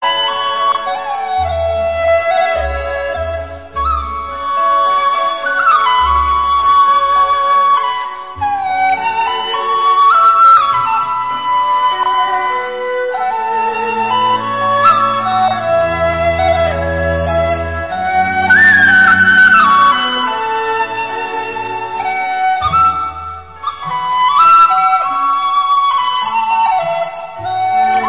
Chinese Ringtones Mp3